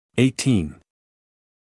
[ˌeɪ’tiːn][ˌэй’тиːн]восемнадцать